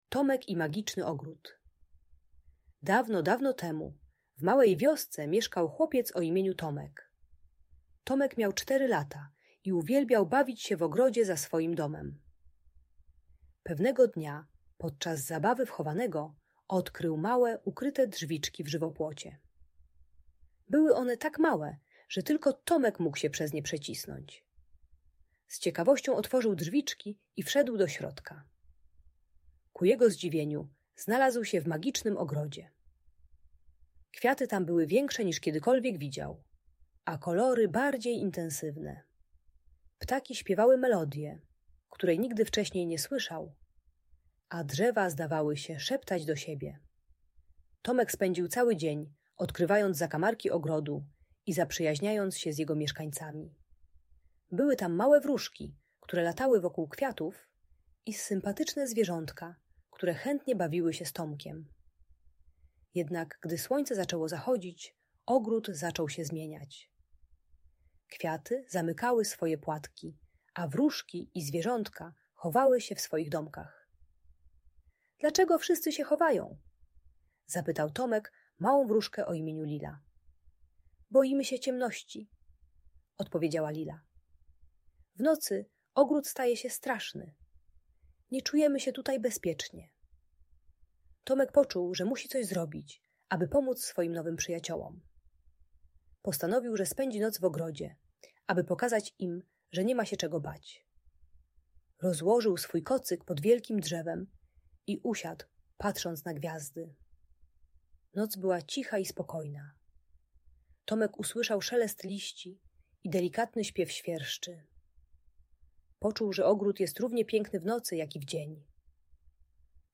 Opowieść o Tomku i Magicznym Ogrodzie - Audiobajka